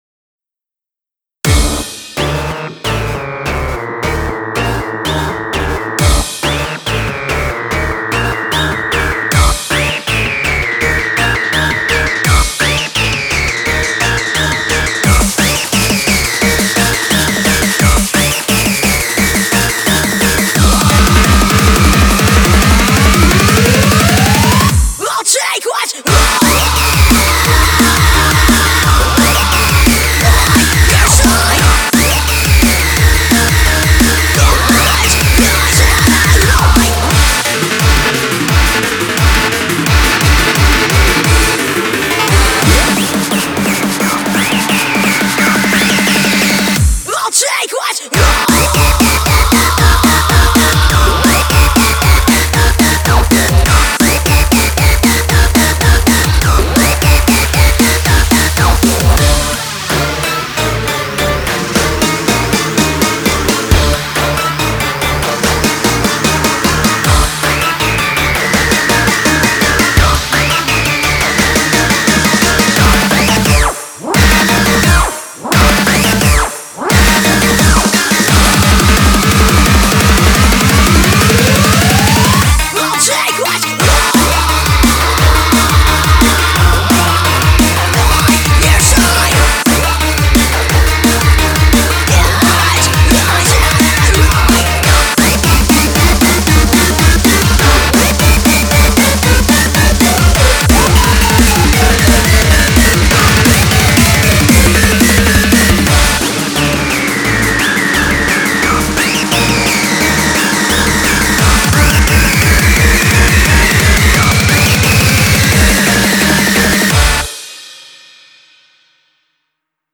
BPM83-174
Audio QualityPerfect (High Quality)
Comments[FREEFORM HARDCORE]